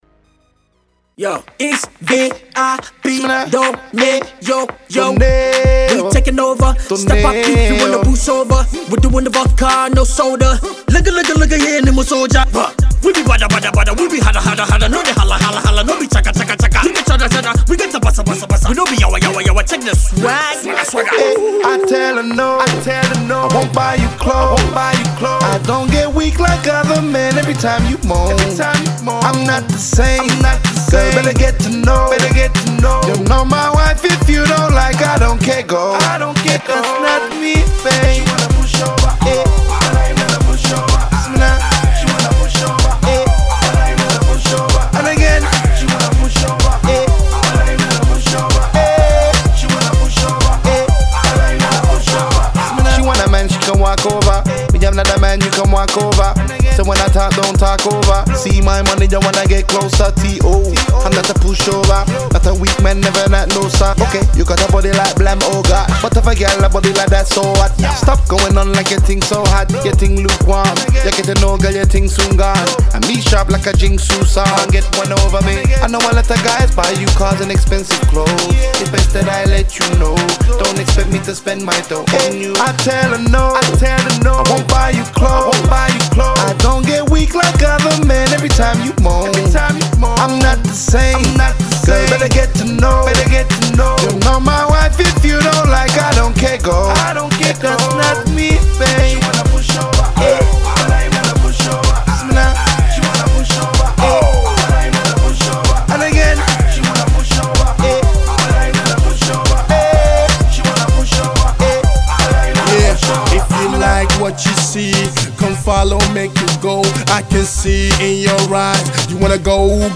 on their bumping new single